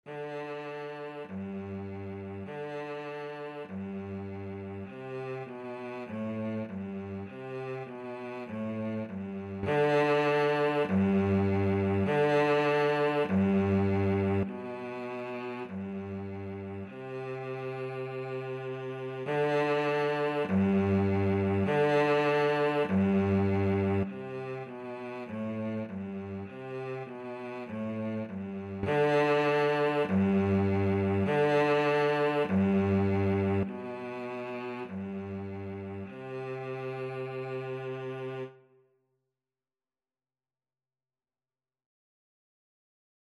4/4 (View more 4/4 Music)
G3-D4
Instrument:
Cello  (View more Beginners Cello Music)
Classical (View more Classical Cello Music)